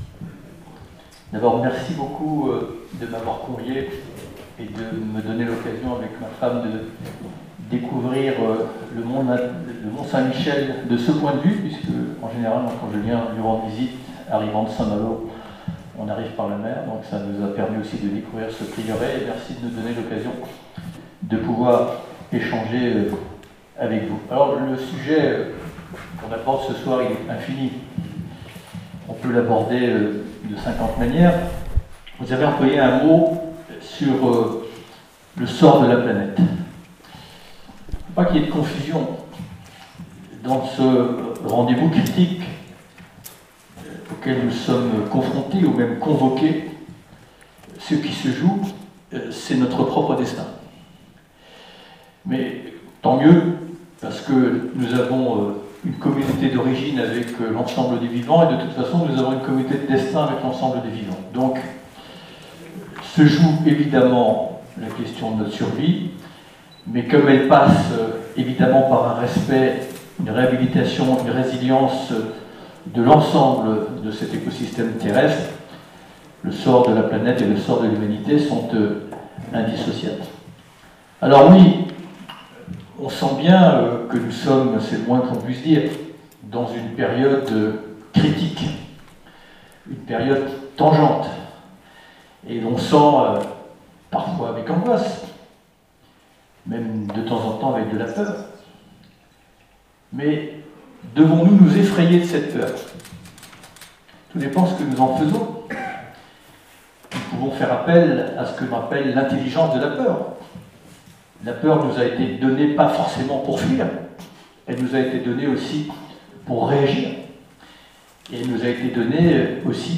agir pour la planete : conférence — Pélerins Mont Saint Michel